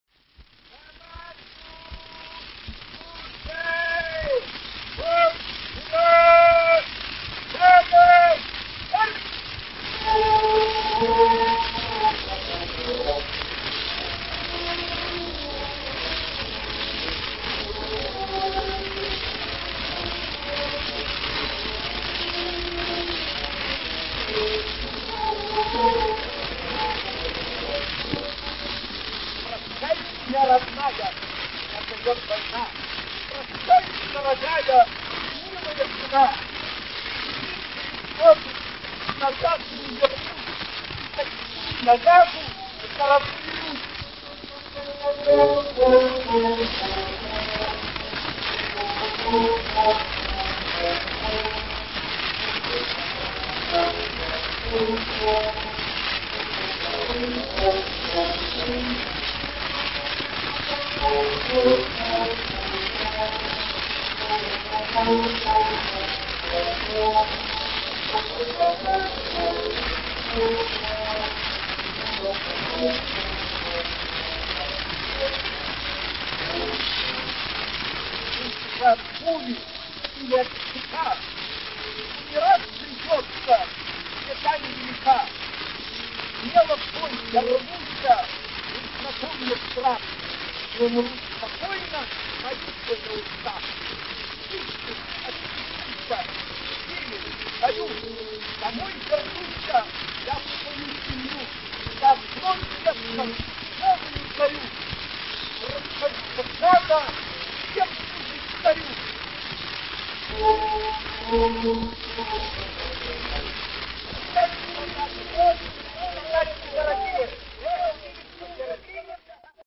И ещё одно произведение времён русско-японской войны, которое все мы привыкли считать насквозь русским, есть в том еврейском сборнике 1916 года: знаменитый марш «Тоска по Родине», сверхпопулярный в те годы отнюдь не только на еврейских свадьбах, но и во всём тогдашнем русском обществе.
Эта заезженная грампластинка была выпущена в самом начале Первой мировой войны.
Мелодекламация «Прощание ратника» — на фоне марша «Тоска по Родине»